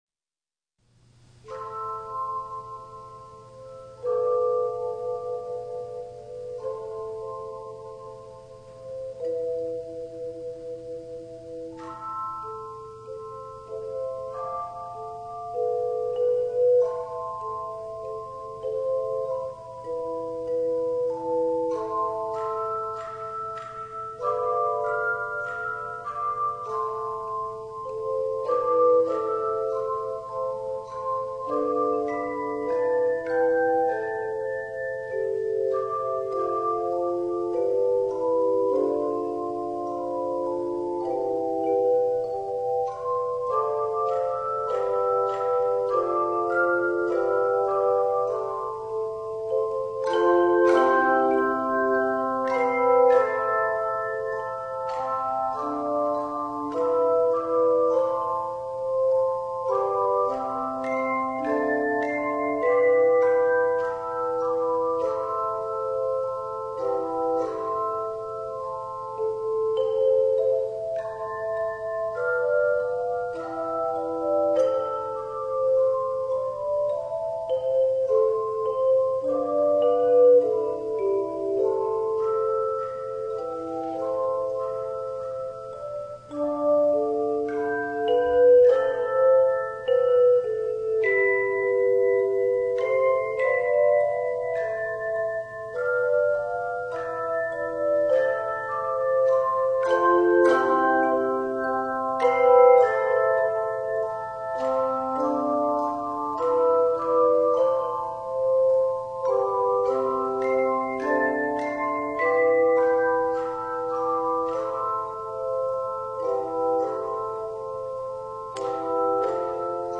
Voicing: Handchimes